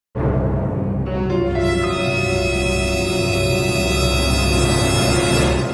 事件
事件効果音.mp3